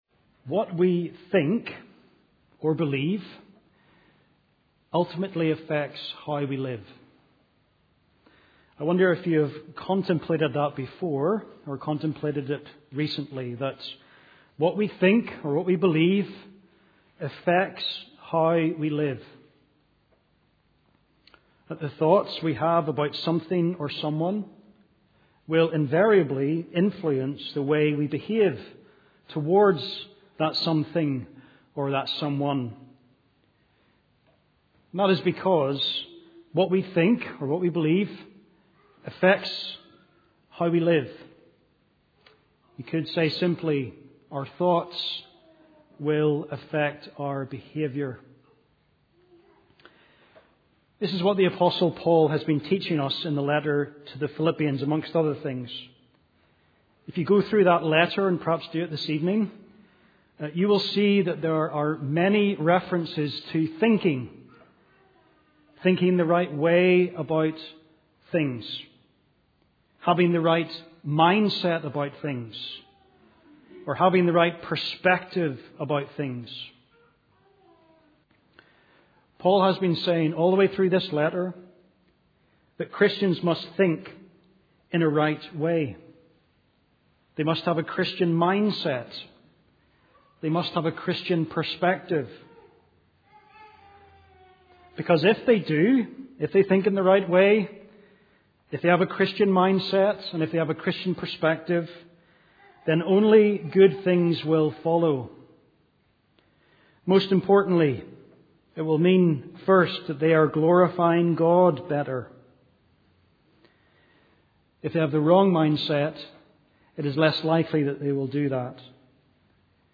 Sermons - Immanuel Presbyterian Church